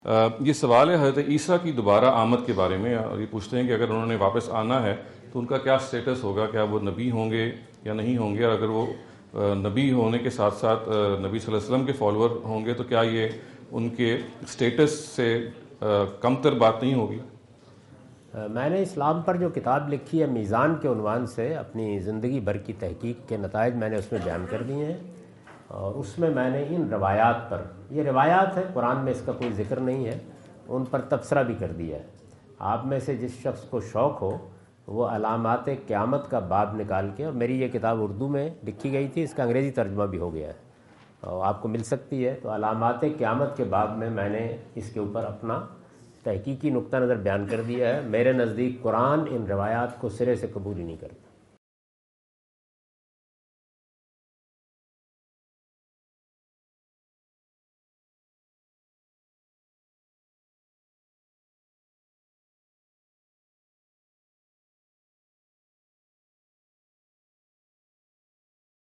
Category: English Subtitled / Questions_Answers /
Javed Ahmad Ghamidi answer the question about "Return of Jesus (sws)" asked at Corona (Los Angeles) on October 22,2017.
جاوید احمد غامدی اپنے دورہ امریکہ 2017 کے دوران کورونا (لاس اینجلس) میں "حضرت مسیح کی آمد ثانی" سے متعلق ایک سوال کا جواب دے رہے ہیں۔